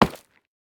Minecraft Version Minecraft Version 1.21.5 Latest Release | Latest Snapshot 1.21.5 / assets / minecraft / sounds / block / nether_ore / break2.ogg Compare With Compare With Latest Release | Latest Snapshot